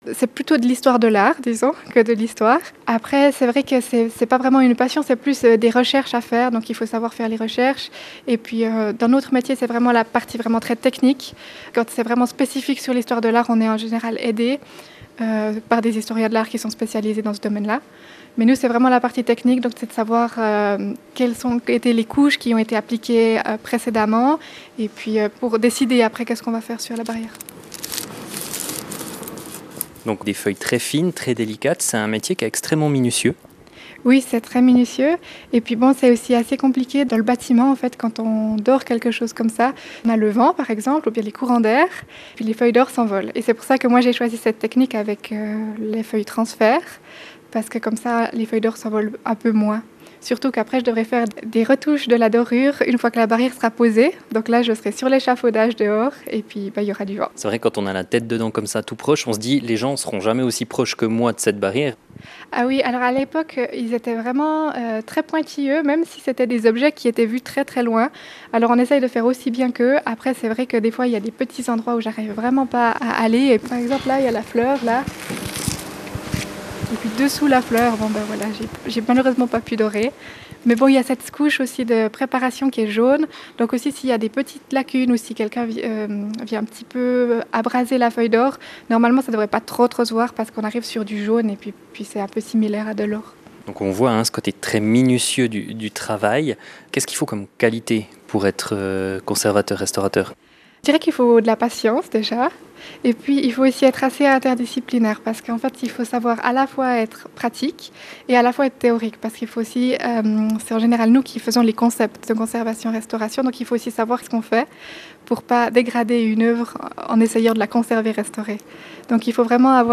Reportage partie 2 par RJB
Son-boulot-conservatrice-restauratrice-RJB-votre-radio-reg1.mp3